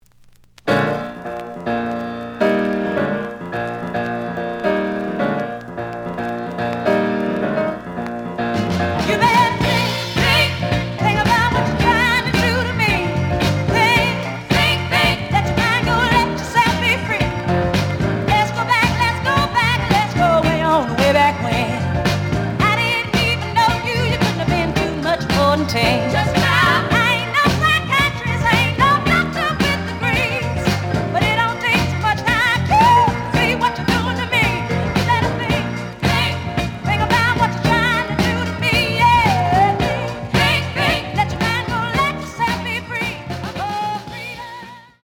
The audio sample is recorded from the actual item.
●Genre: Soul, 60's Soul
Slight damage on both side labels. Plays good.)